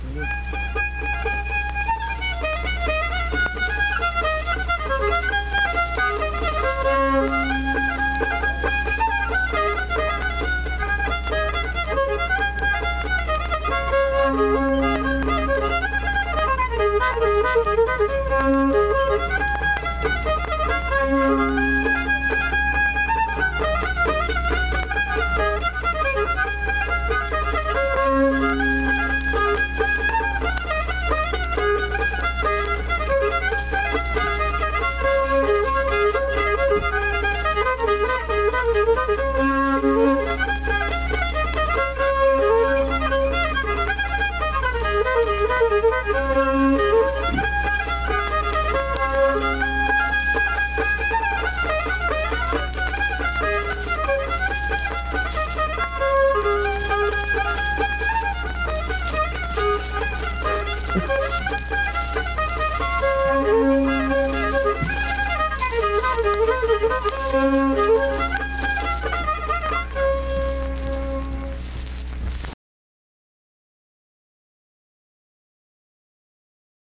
Instrumental fiddle performance.
Instrumental Music
Fiddle
Vienna (W. Va.), Wood County (W. Va.)